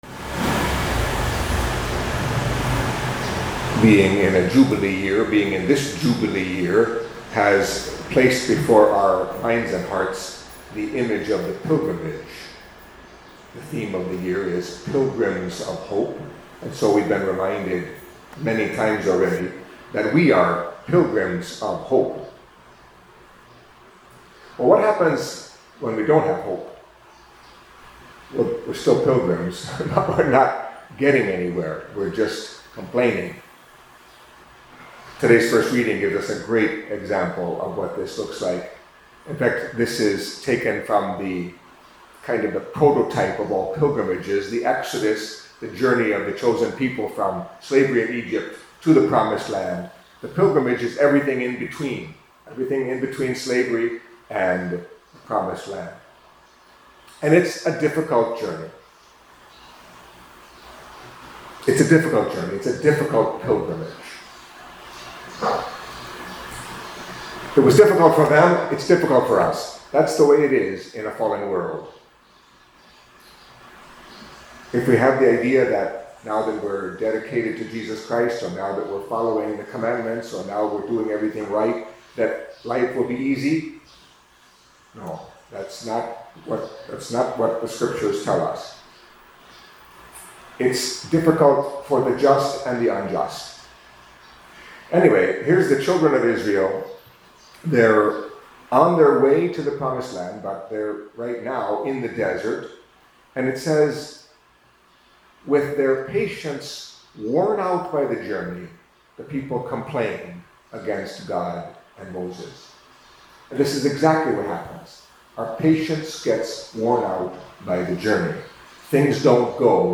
Catholic Mass homily for Tuesday of the Fifth Week of Lent